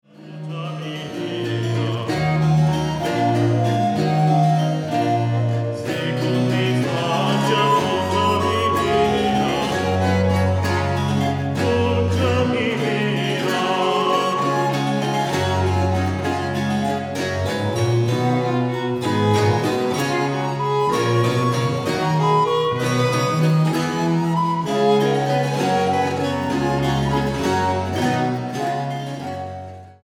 flautas de pico